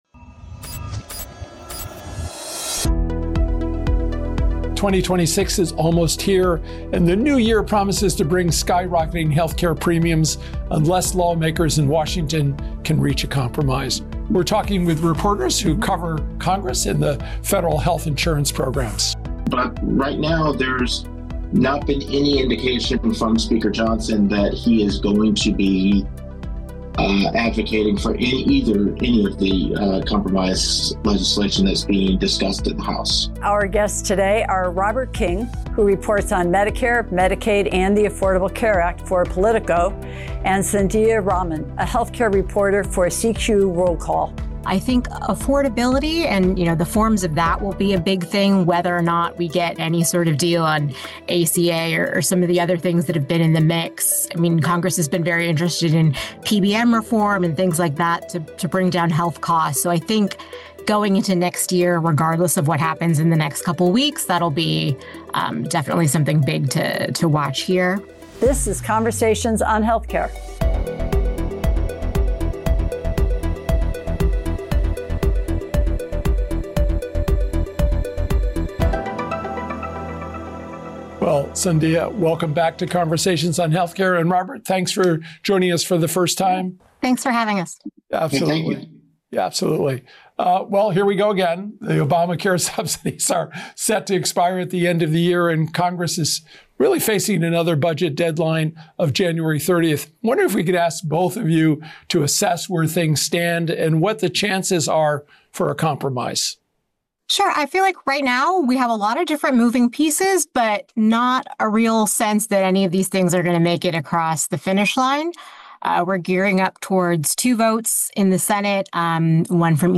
Congressional reporters